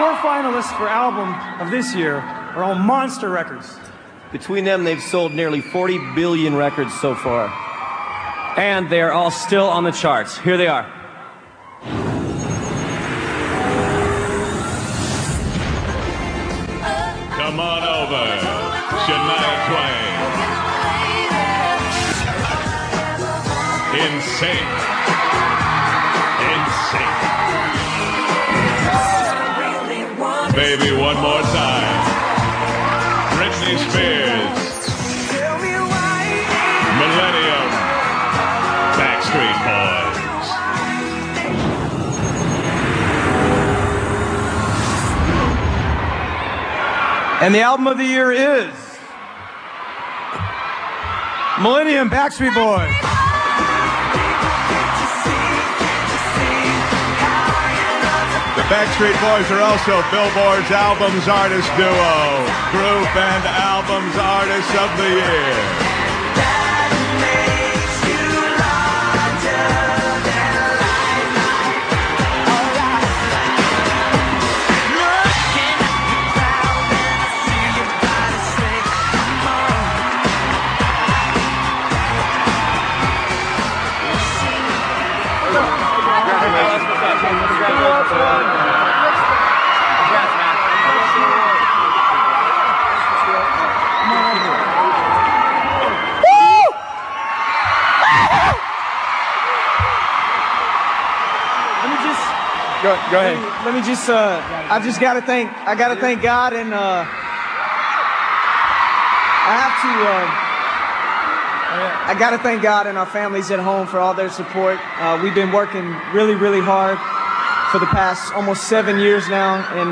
Billboard Awards 3:24 | 529 KB Winning the '99 Album Of The Year award.